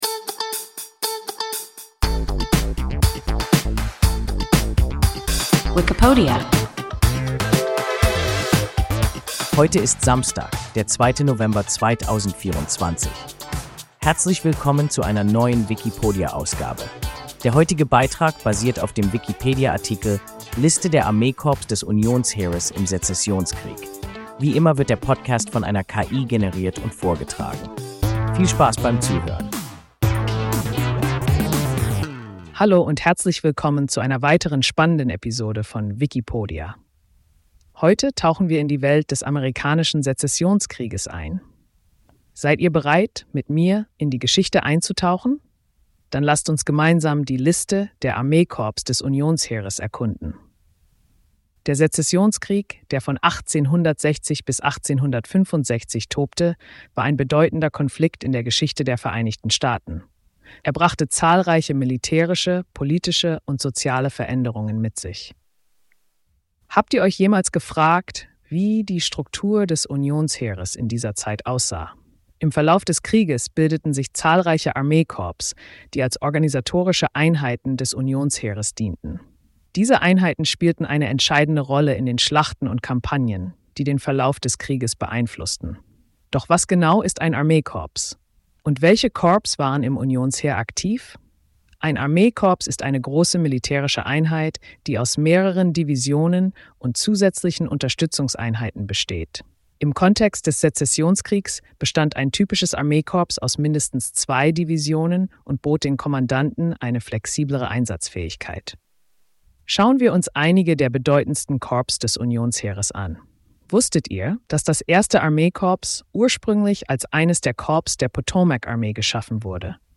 Liste der Armeekorps des Unionsheeres im Sezessionskrieg – WIKIPODIA – ein KI Podcast